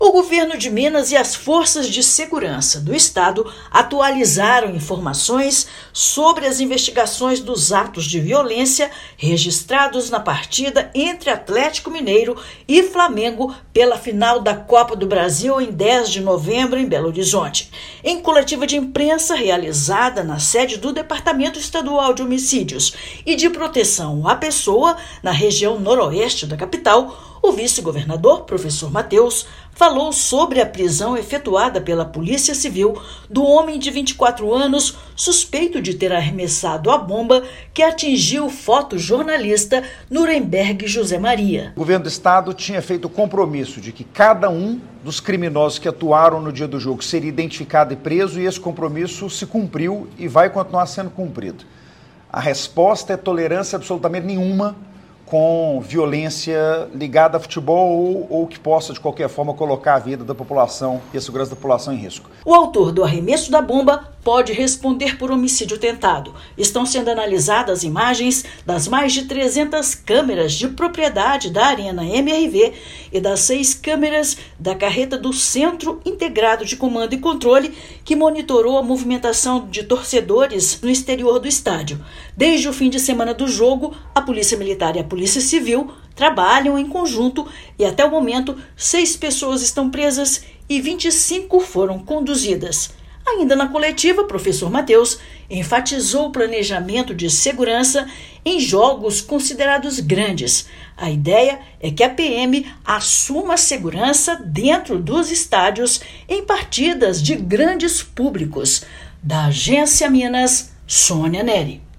Desde o ocorrido na Arena MRV em 10/11, seis pessoas já foram detidas; investigações continuam para localização de outros envolvidos. Ouça matéria de rádio.